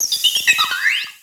Cri de Fragilady dans Pokémon X et Y.